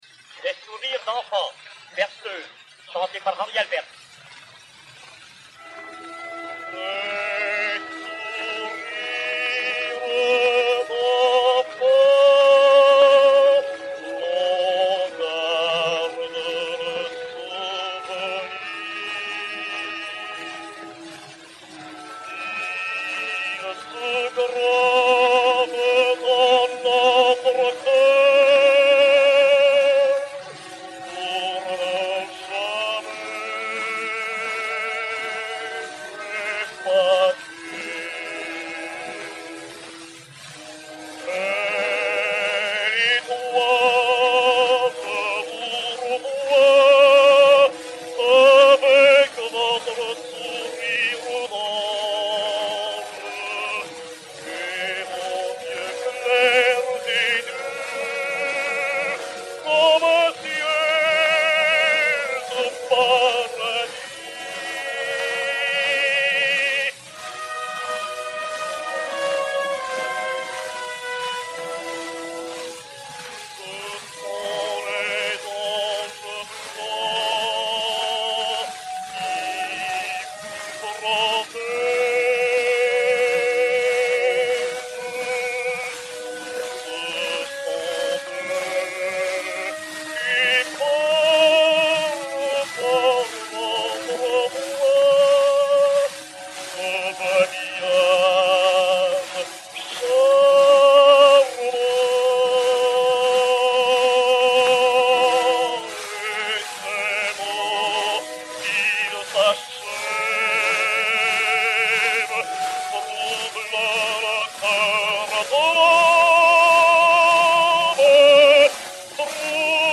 Berceuse "Des sourires d'enfants"
Henri Albers et Orchestre
Pathé 90 tours n° 920, enr. en 1910/1912